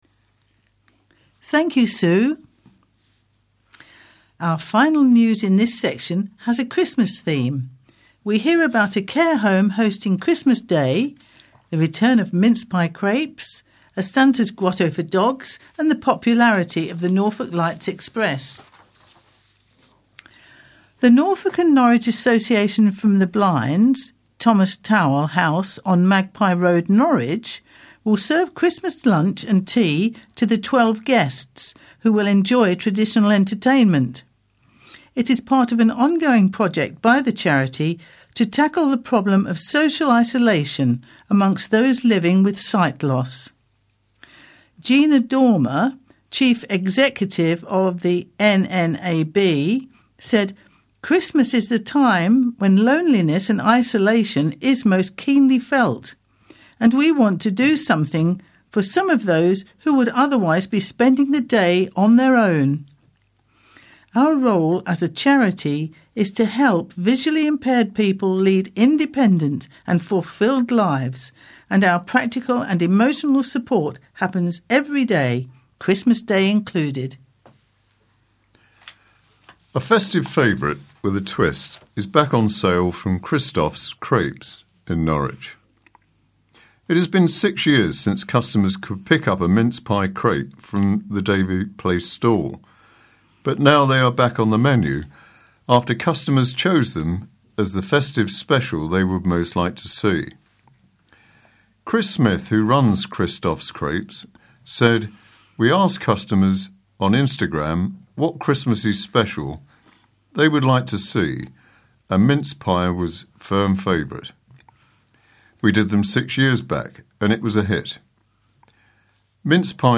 Chatterbox Norwich Talking Newspaper Petersen House 240 King Street Norwich NR1 2TT